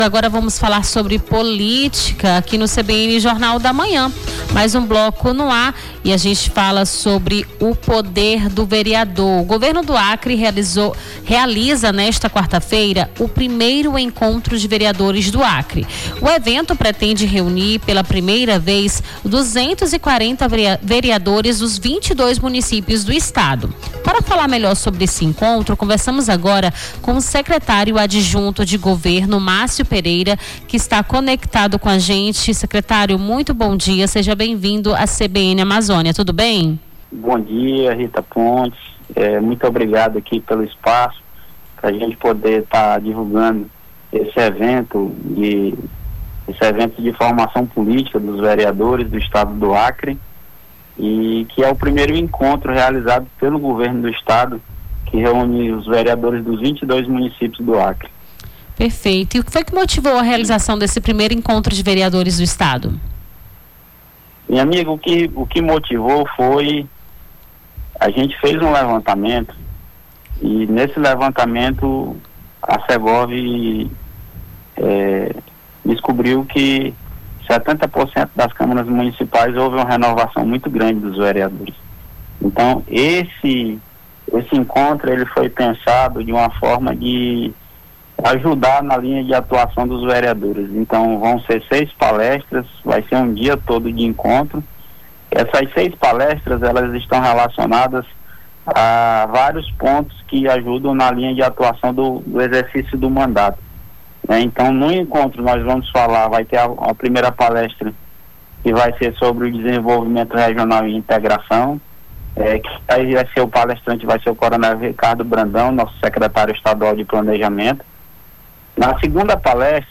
Baixar Esta Trilha Nome do Artista - CENSURA - ENTREVISTA ENCONTRO VEREADORES (04-06-25).mp3 .